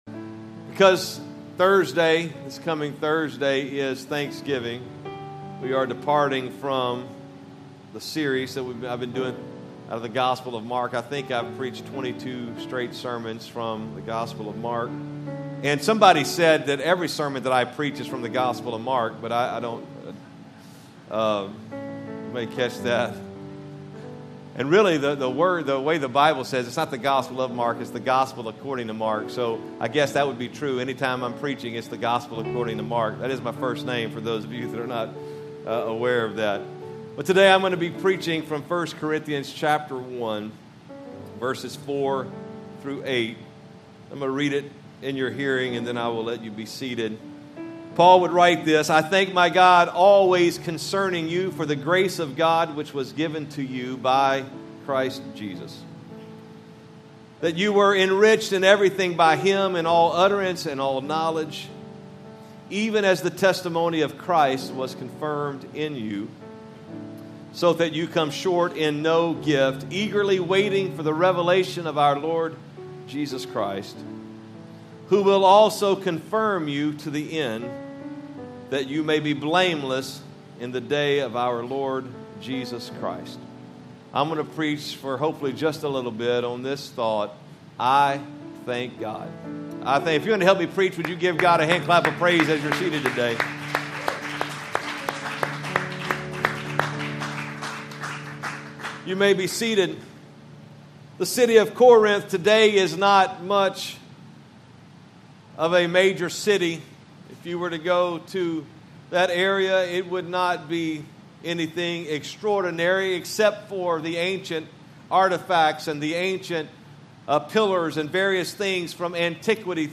Sermons | Cross Church Kansas City